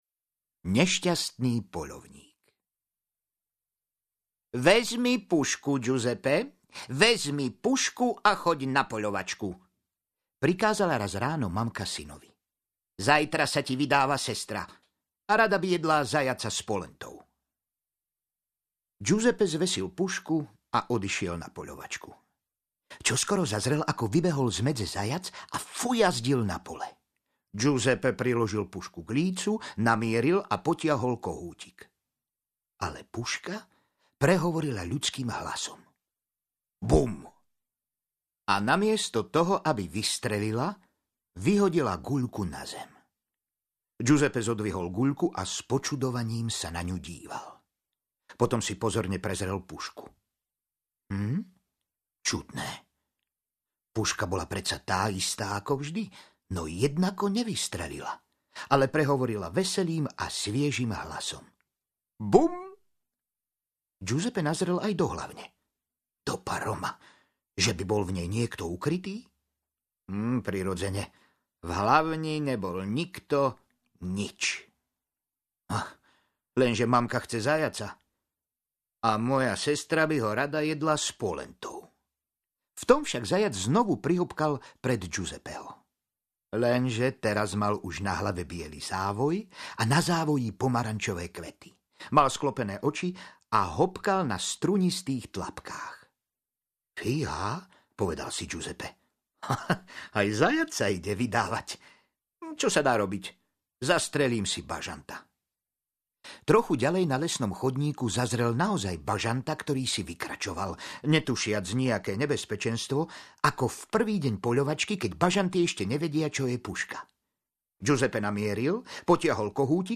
Rozprávky po telefóne audiokniha
Ukázka z knihy